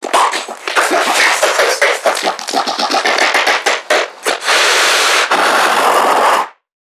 NPC_Creatures_Vocalisations_Infected [56].wav